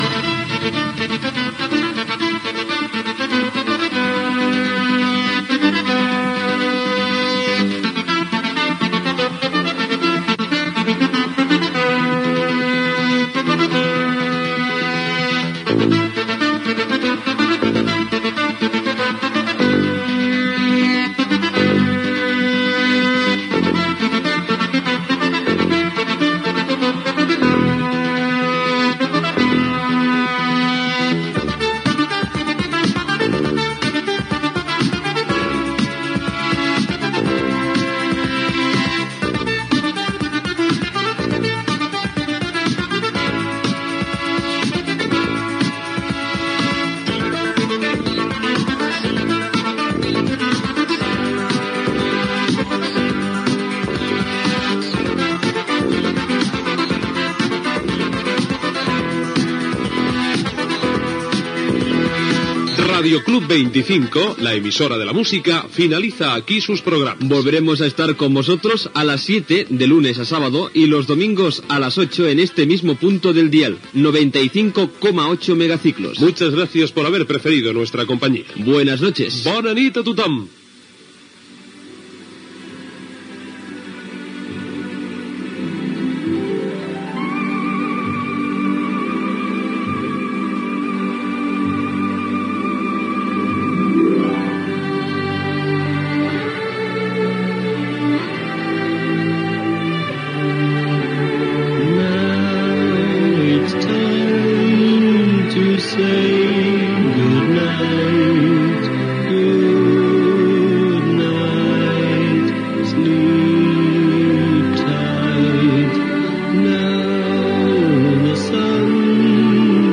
Tancament de l'emissió i tema "Good Night" de The Beatles